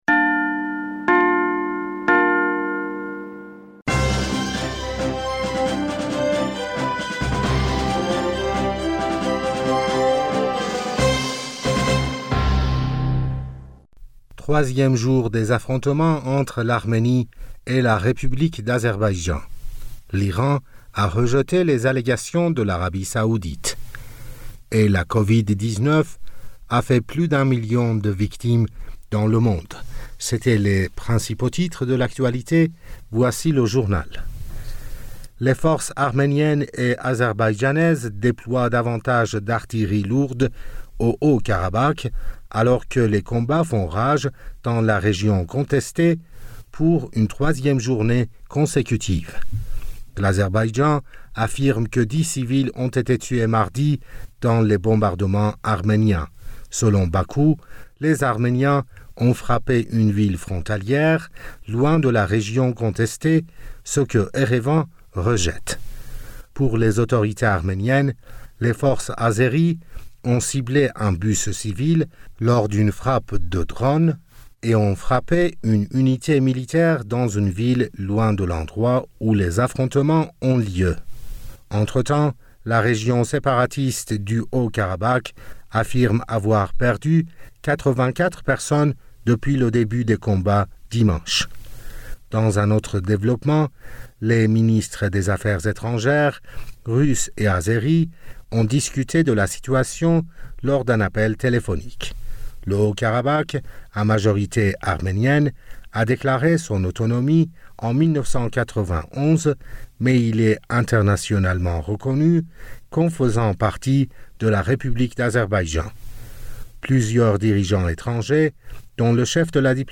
Bulletin d'information du 29 septembre 2020